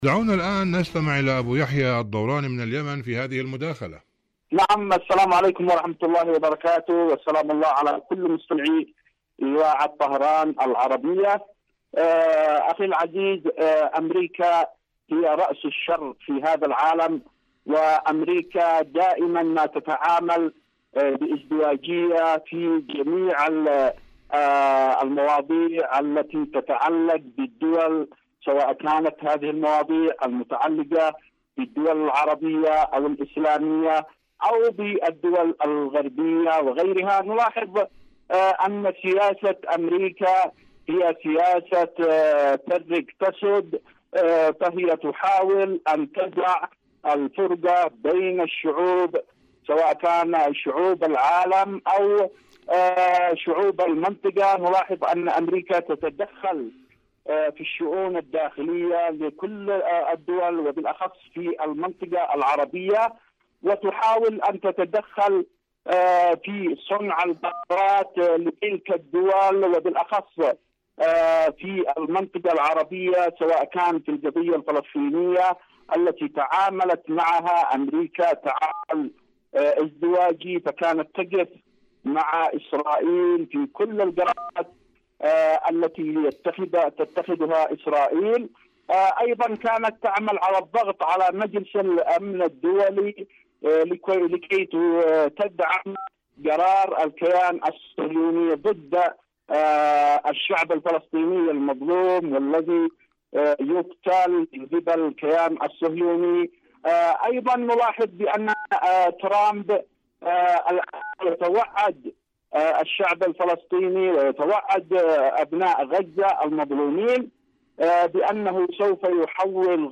ازدواجية المعايير الأمريكية.. مقابلة